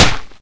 somen_drop.ogg